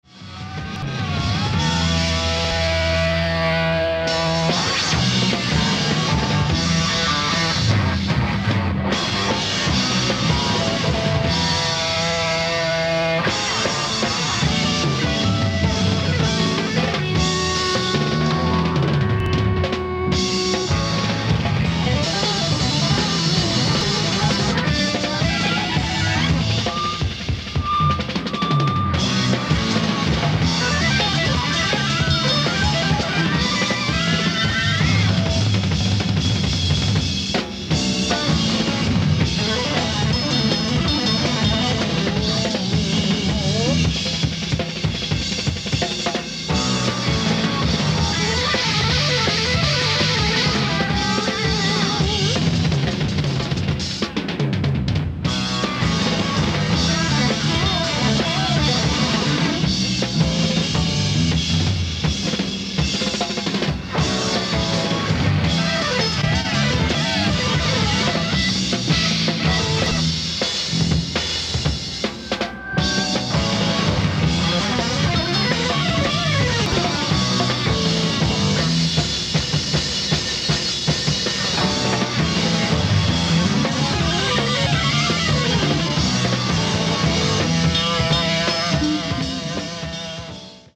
ライブ・アット・トリード大学、オハイオ 11/29/1975
※試聴用に実際より音質を落としています。